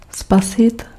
Ääntäminen
US Tuntematon aksentti: IPA : /seɪv/